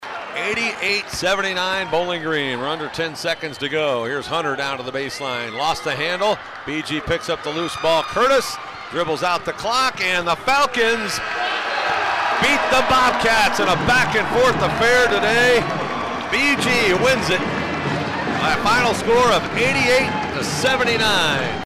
RADIO CALLS